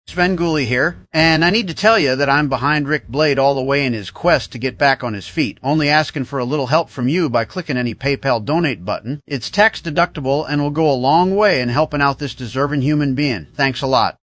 Voice Over Jobs
EndorsementSvengoolie.mp3